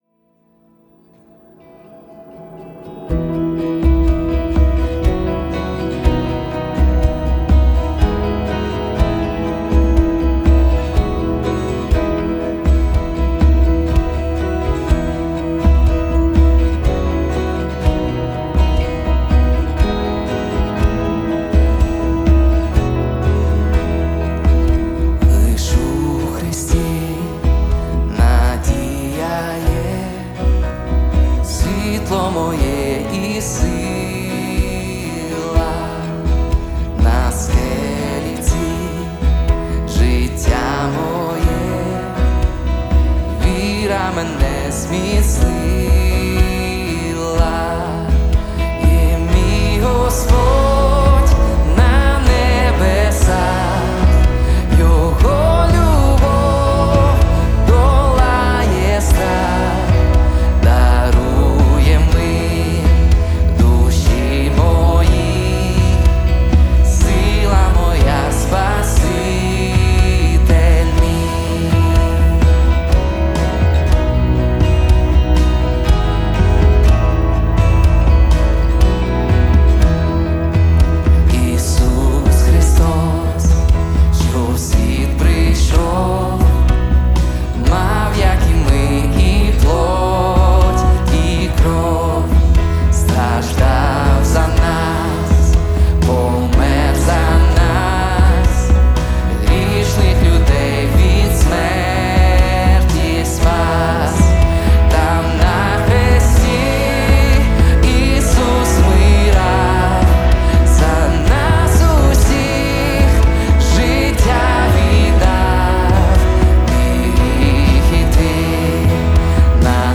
179 просмотров 40 прослушиваний 3 скачивания BPM: 61